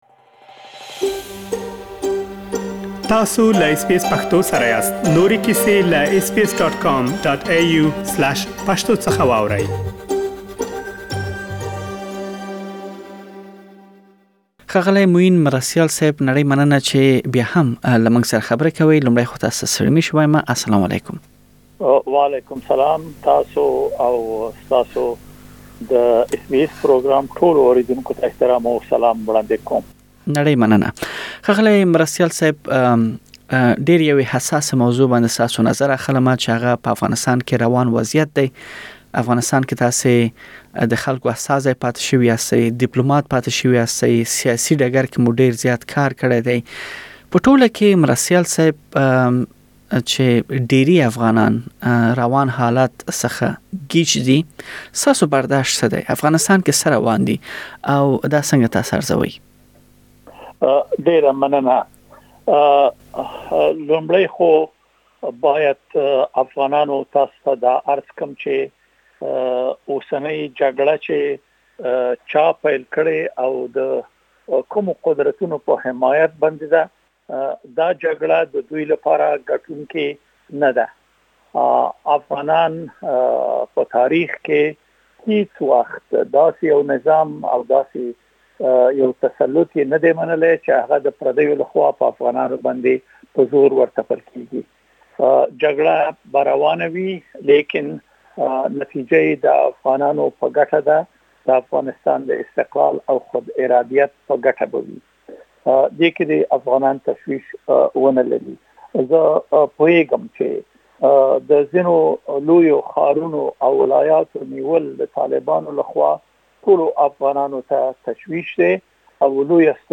دا او نور ډير مهم مسايل مو پدې مرکه کې راخيستي بشپړه مرکه واورئ.